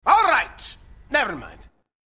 Voice 2 -
Sex: Male